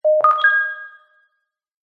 High tech beep with reverb 7 Sound Effect - Download FREE - ZapS.mp3